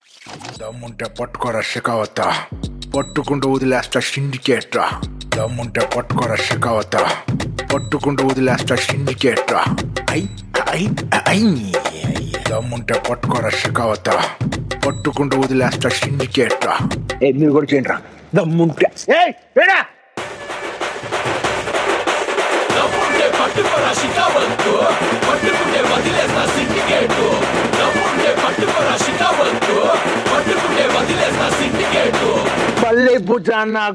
best flute ringtone download | mass song ringtone